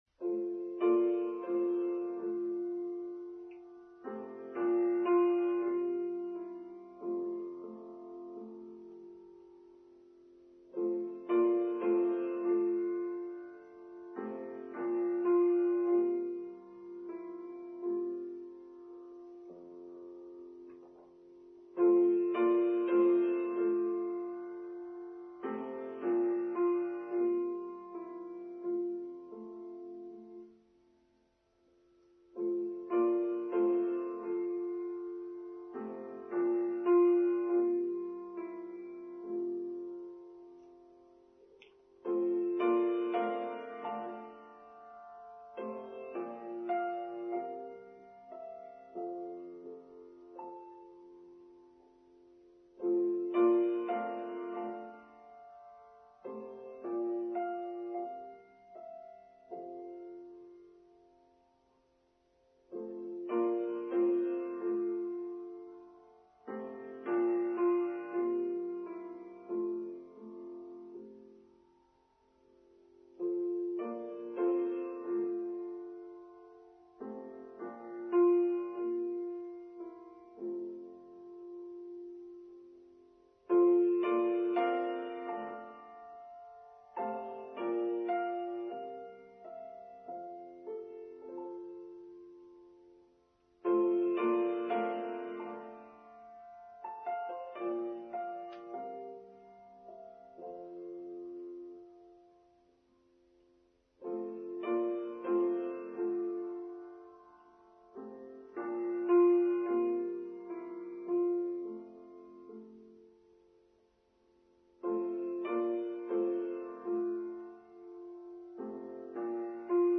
Sabbath Rest and Silence: Online Service for Sunday 11th June 2023